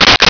Sfx Pod Switch B
sfx_pod_switch_b.wav